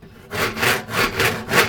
sawwood.ogg